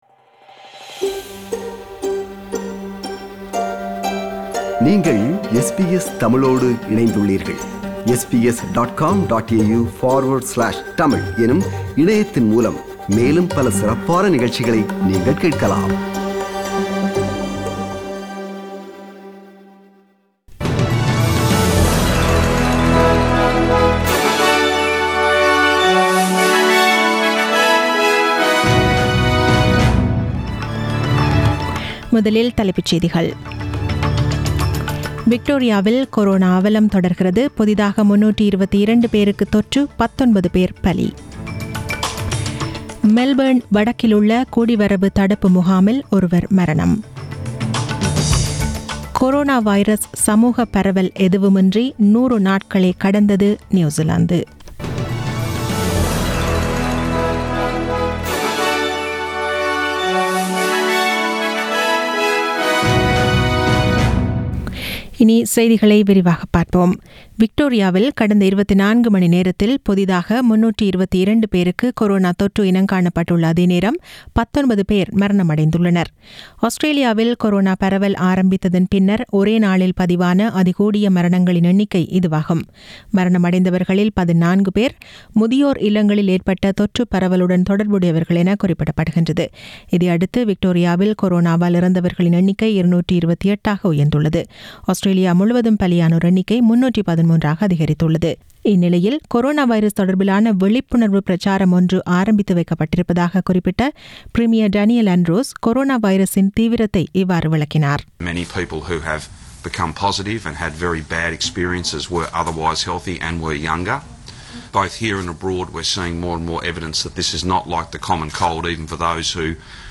The news bulletin was aired on 10 August 2020 (Monday) at 8pm.